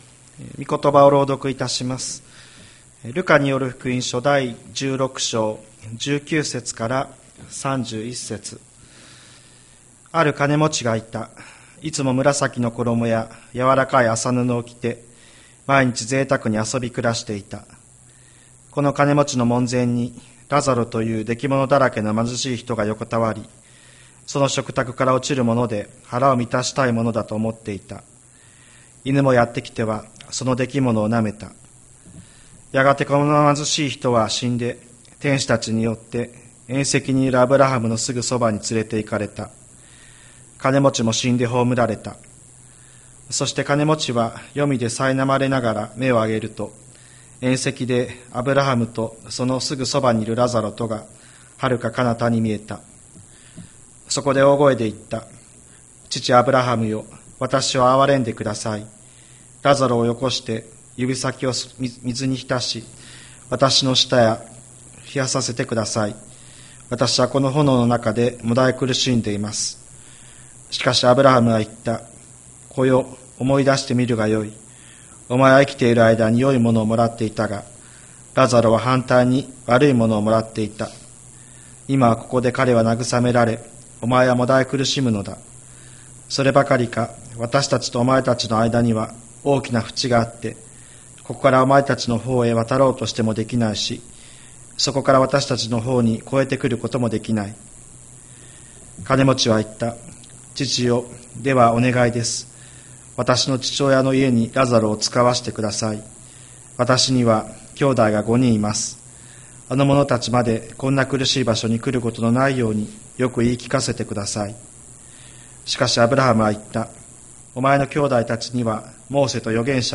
千里山教会 2023年12月31日の礼拝メッセージ。